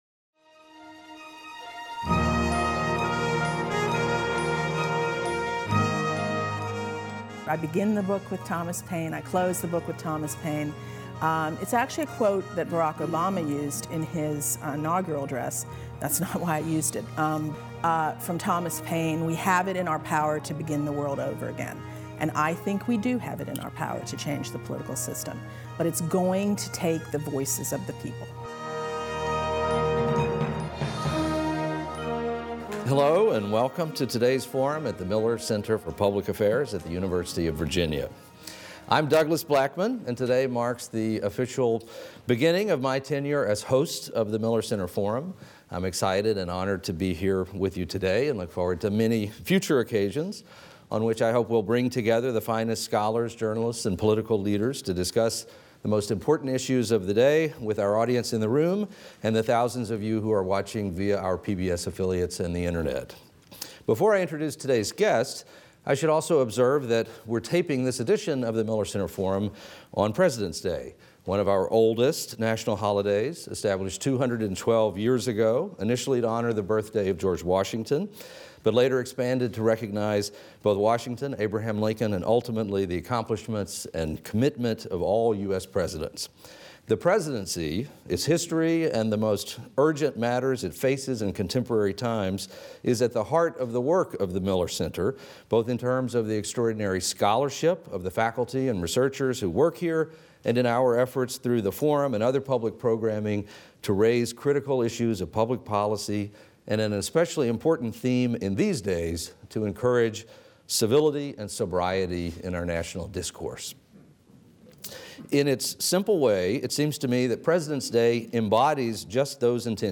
This will be the first Forum moderated by Douglas Blackmon, the Miller Center's new Forum chair.